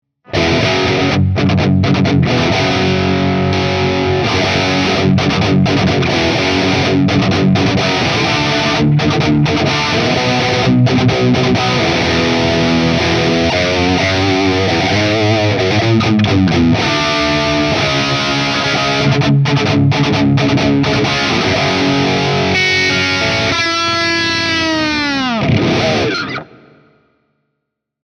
Tutte le clip audio sono state registrate con amplificatori reali iniziando con Ignition spento nei primi secondi per poi accenderlo fino alla fine della clip.
Chitarra: Fender Stratocaster (pickup al ponte)
Testata: Marshall JCM800 sul canale High e gain a 8/10
Cassa: Marshall 1960 con coni Celestion G12T75